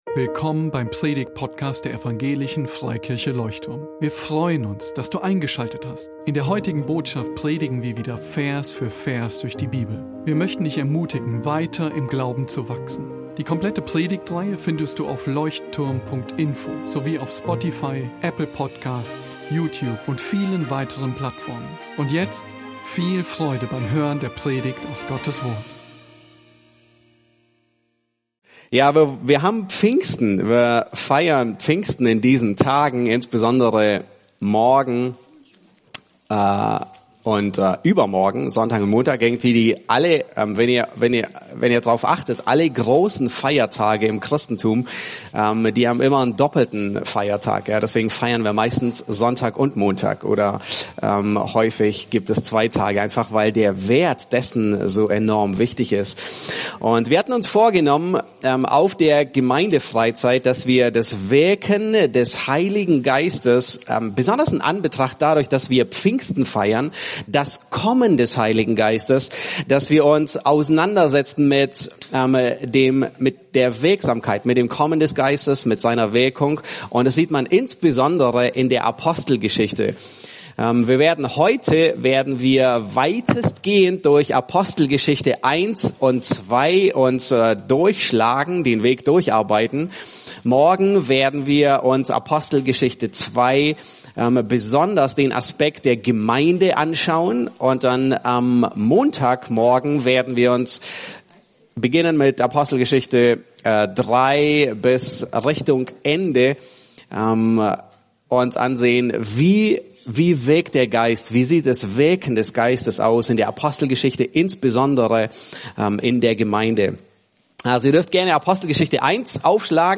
Gemeindefreizeit 2022 | Vortrag 1